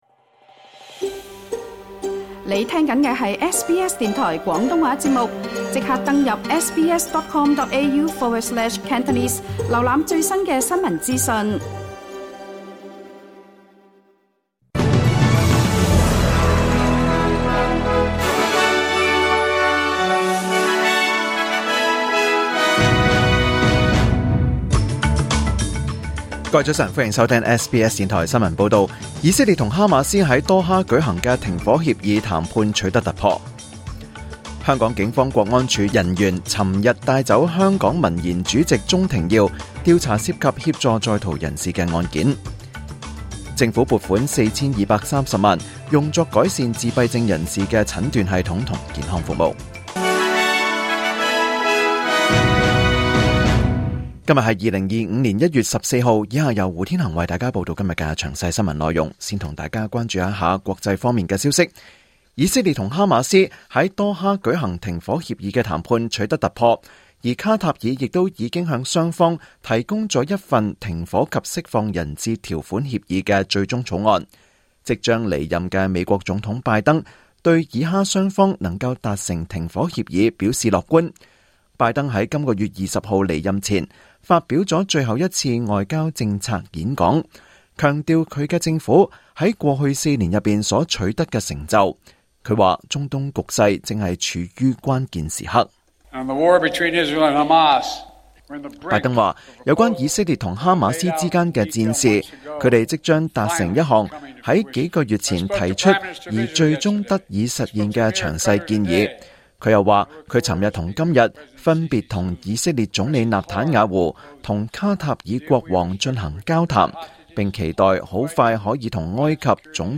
2025 年 1 月 14 日 SBS 廣東話節目詳盡早晨新聞報道。